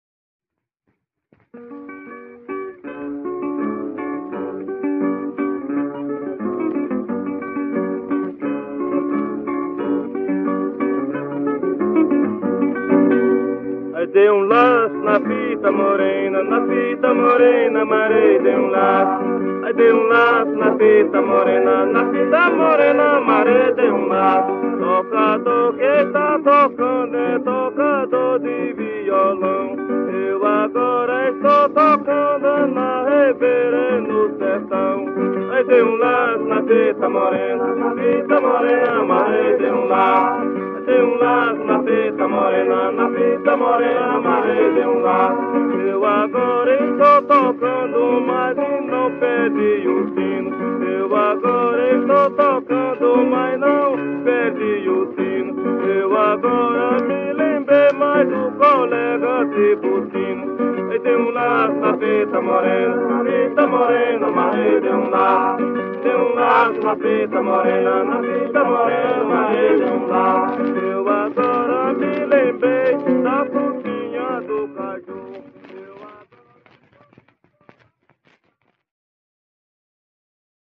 Coco embolada -“”Laço de fita morena”” - Acervos - Centro Cultural São Paulo